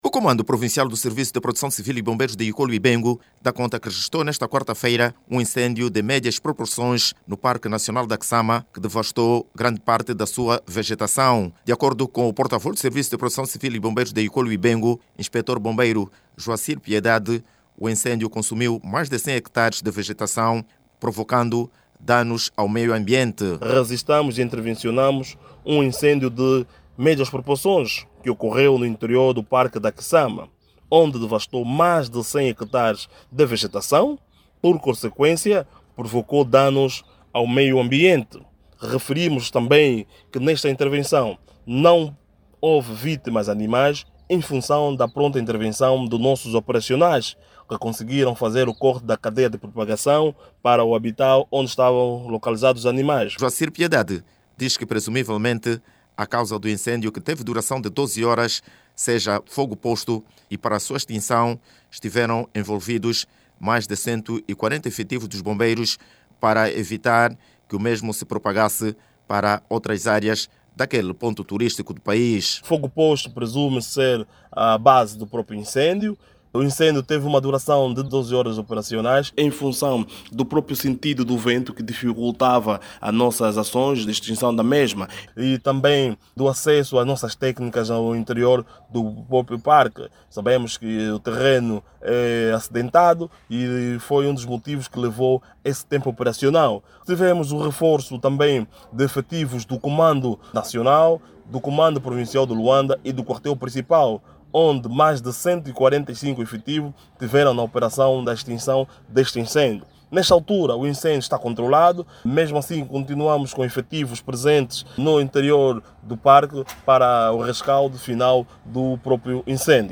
Cem hectares de vegetação do parque nacional da Quiçama, foram destruídos em consequência de um incêndio que durou mais 12 horas, nesta ontem, quarta-feira(17). Os Serviços de Protecção Civil e Bombeiros, dizem que o incêndio esta controlado e que o mesmo não causou a perda de vida animal. Fogo posto é apontado como principal causa e a sua extinção teve o envolvimento de mais de 140 efectivos. Saiba mais dados no áudio abaixo com o repórter